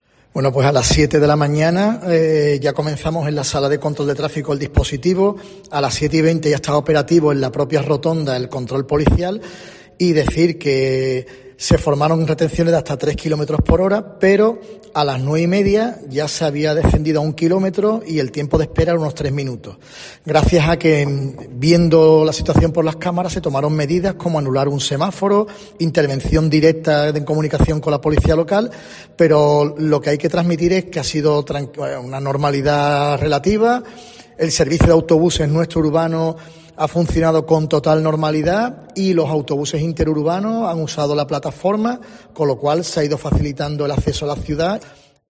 El concejal de Movilidad del Ayuntamiento de Cádiz, José Manuel Verdulla, habla del plan de tráfico